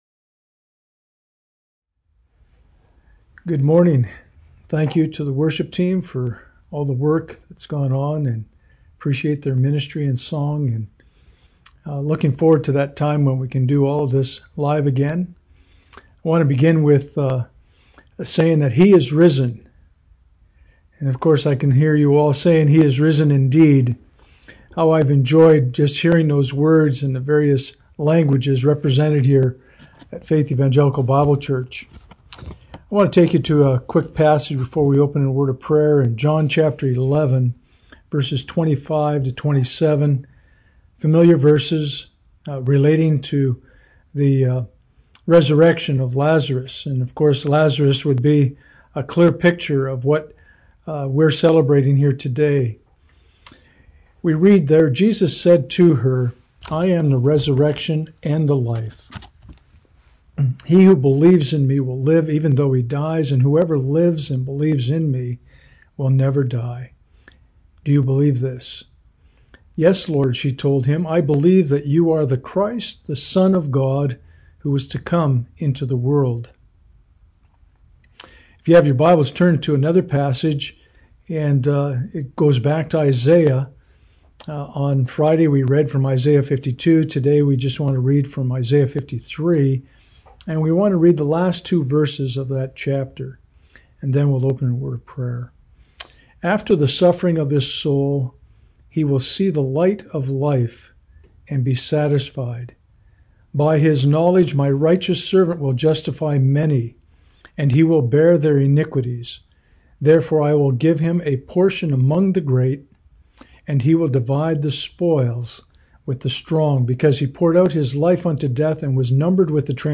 Passage: John 11:25-27 Service Type: Sunday Morning « Good News